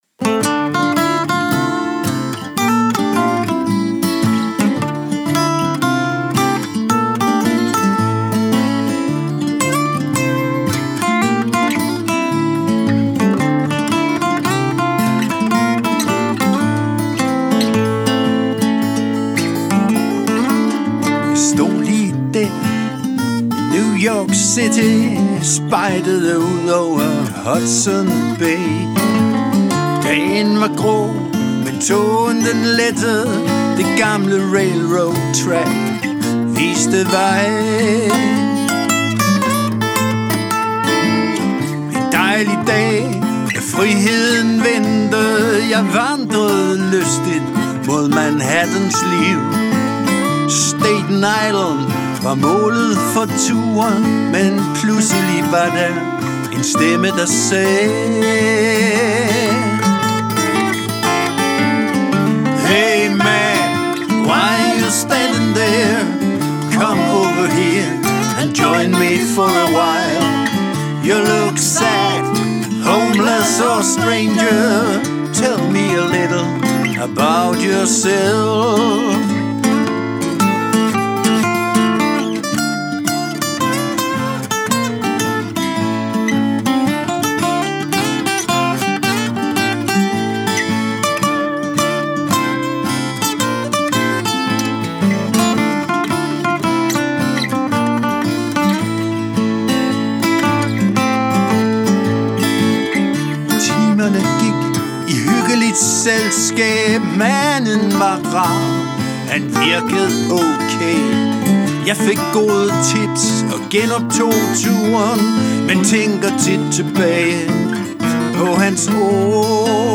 Vi er en swingende duo, der gennem mange år har præget den danske musikscene. Vi er to guitarister.
Også her benytter vi os af backtracks, så musikken fylder lidt mere.